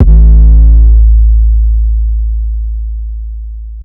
Waka KICK Edited (67).wav